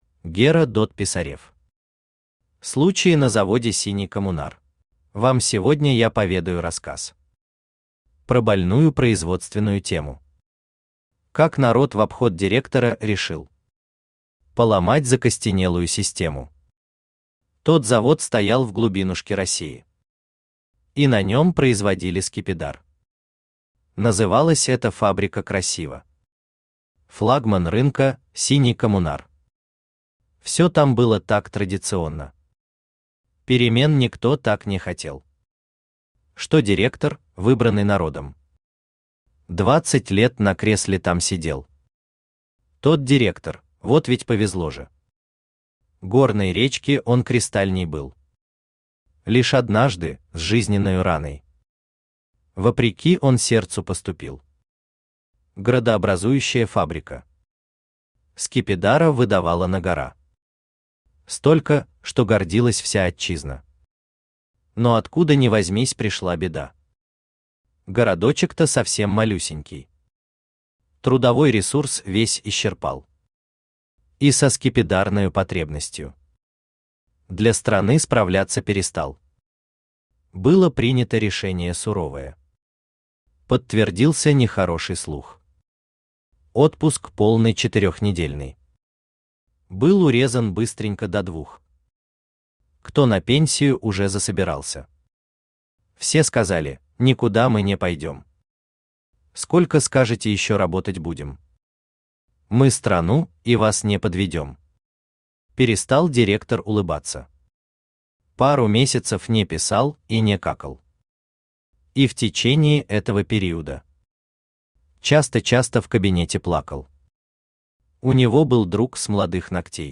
Аудиокнига Случай на заводе «Синий коммунар» | Библиотека аудиокниг